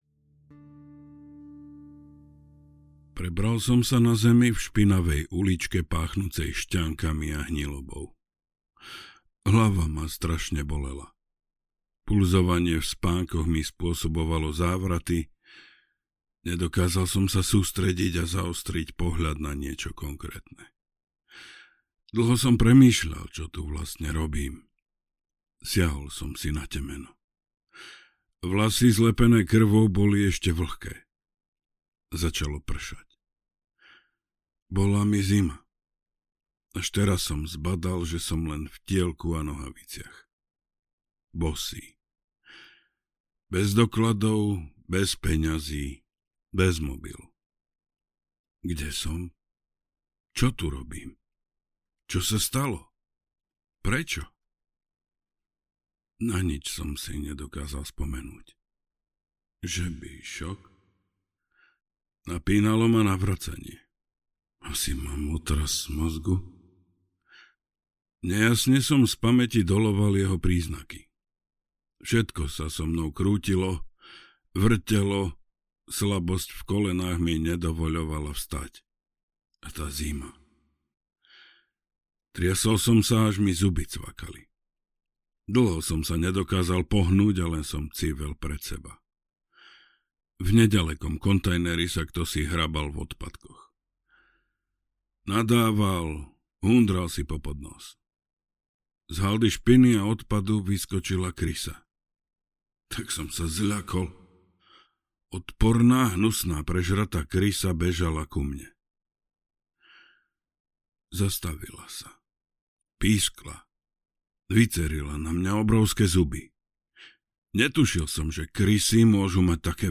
Slovenské temno audiokniha
Ukázka z knihy